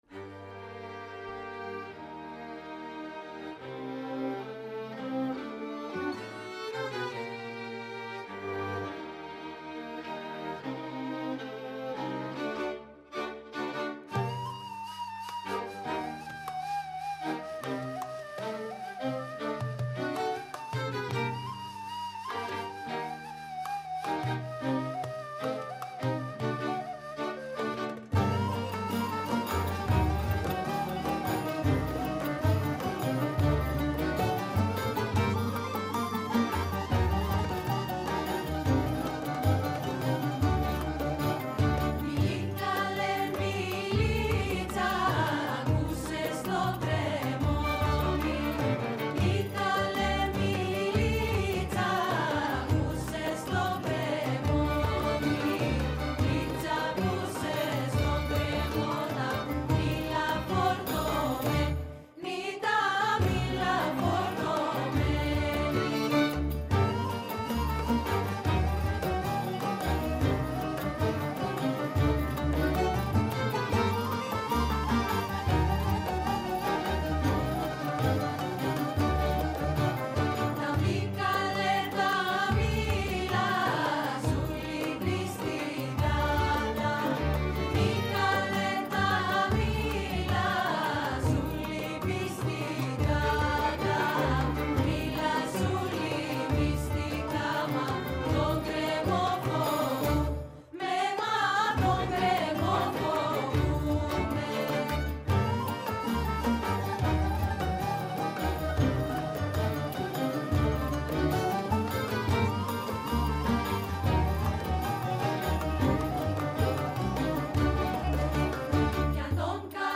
Συνέντευξη